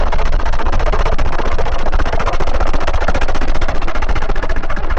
Helicopter Hovering
Steady helicopter rotor chop while hovering in place with turbine whine underneath
helicopter-hovering.mp3